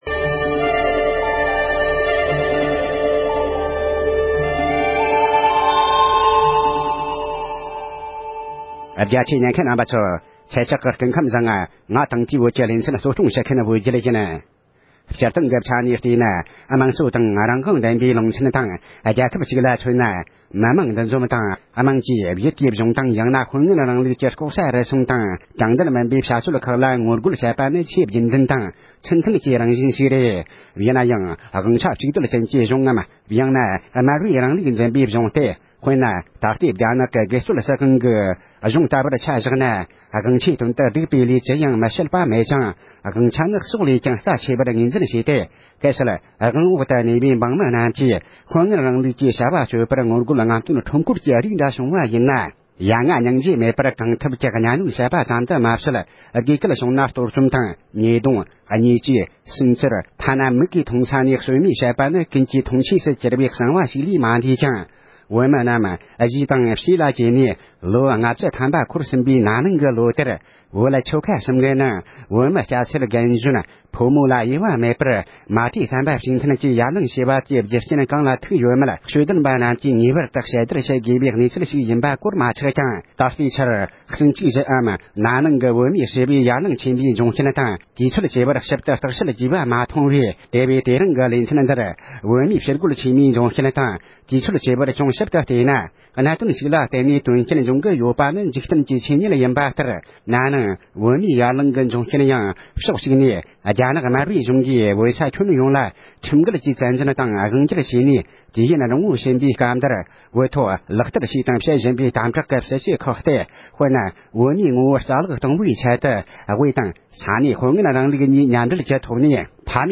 ཕྱི་ལོ་ཉིས་སྟོང་བརྒྱད་ལོའི་བོད་ནང་གི་གཞི་རྒྱ་ཆེ་བའི་ཞི་རྒོལ་གྱི་འབྱུང་རྐྱེན་དང་དུས་ཚོད་བཅས་ལ་སྒེར་གྱི་བལྟ་ཚུལ་ཞེས་པའི་ཐོག་གླེང་མོལ།